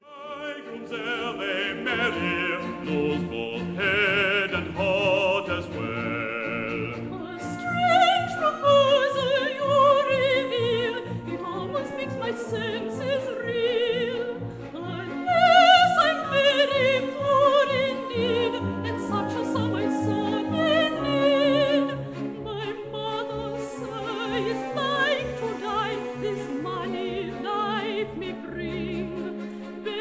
baritone
soprano